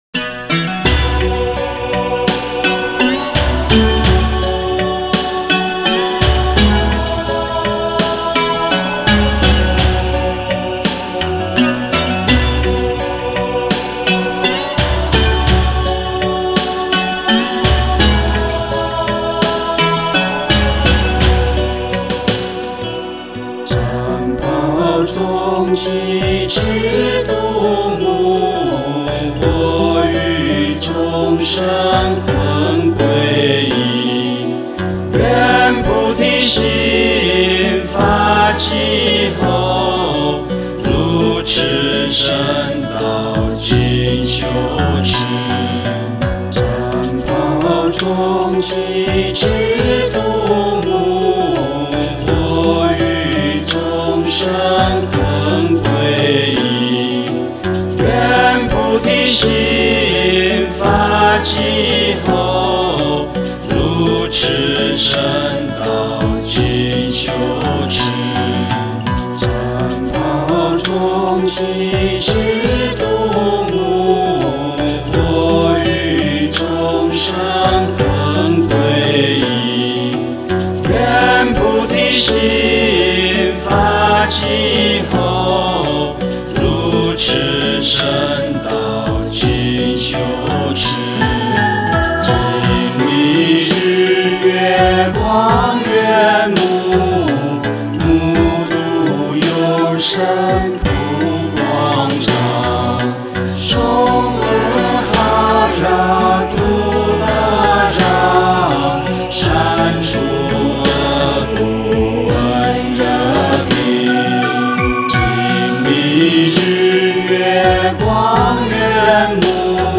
葉衣佛母簡修儀軌(祥和版)